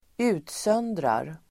Uttal: [²'u:tsön:drar]